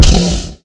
Minecraft Version Minecraft Version 1.21.5 Latest Release | Latest Snapshot 1.21.5 / assets / minecraft / sounds / mob / horse / zombie / hit4.ogg Compare With Compare With Latest Release | Latest Snapshot